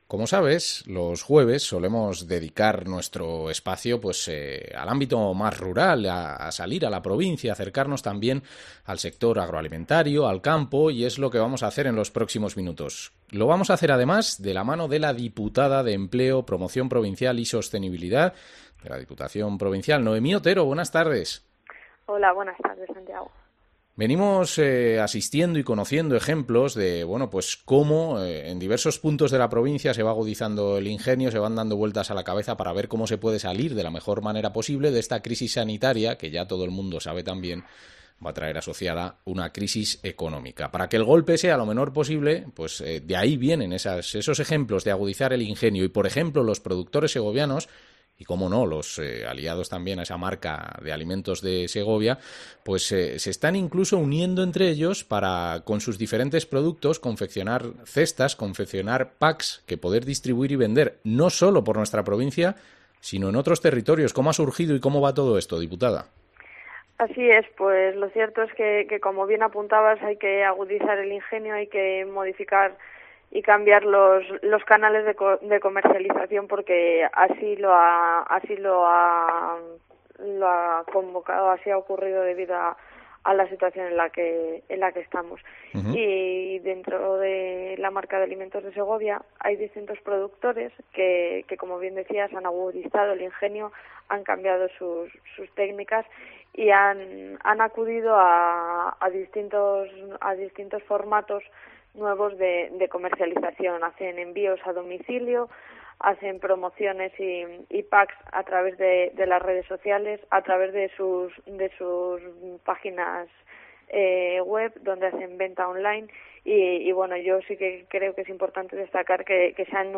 Entrevista a la diputada provincial de Empleo, Promoción Provincial y Sostenibilidad, Noemí Otero